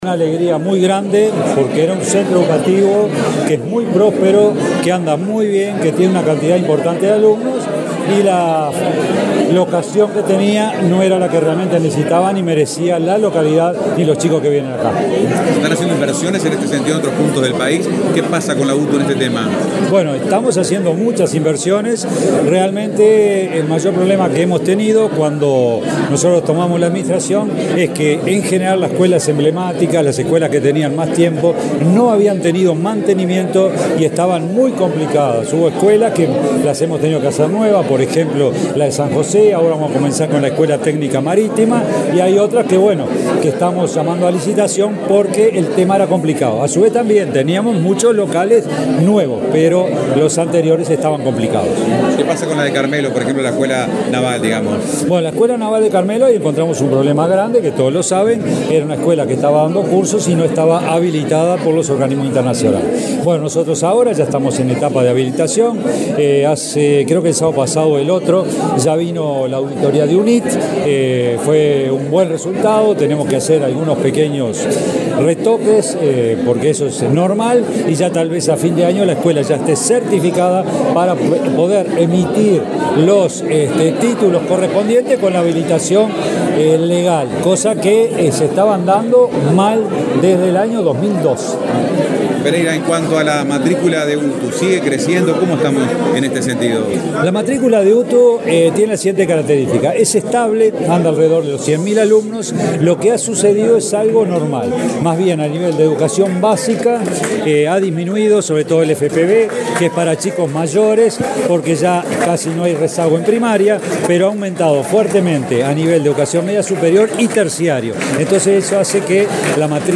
Declaraciones del director general de UTU
Luego dialogó con la prensa.